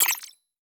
HiTech Click 7.wav